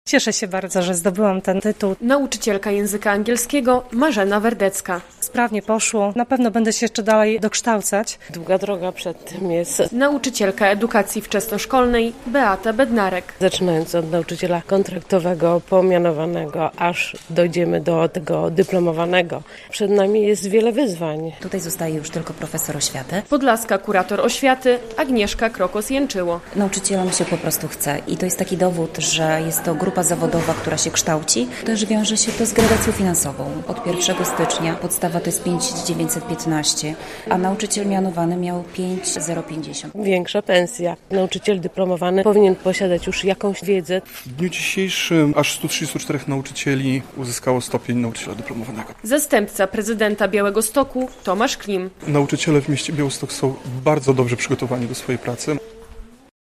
Nauczycielom się po prostu chce i to jest taki dowód, że jest to grupa zawodowa, która się kształci, też wiąże się to z gradacją finansową. Od 1 stycznia podstawa to jest 5915 zł, a nauczyciel mianowany miał 5050 zł - mówi podlaska kurator oświaty Agnieszka Krokos-Janczyło.